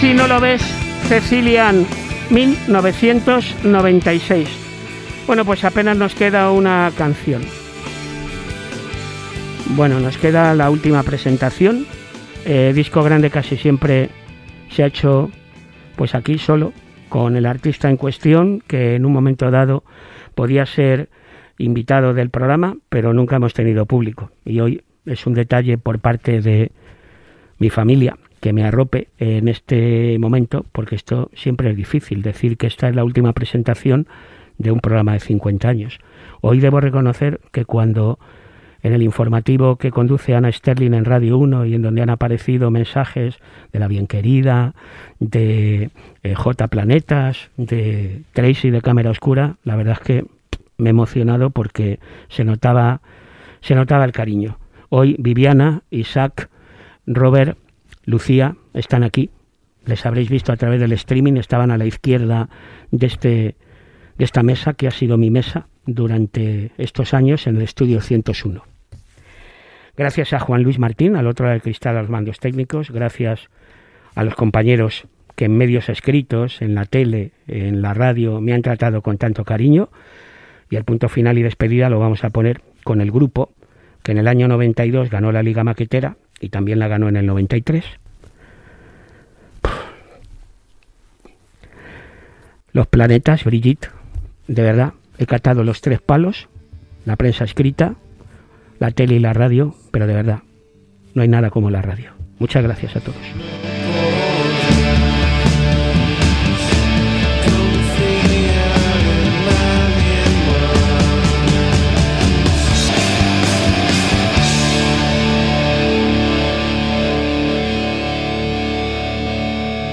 Últimes paraules a l'últim programa, després de 50 anys d'emissió i tema musical.
Musical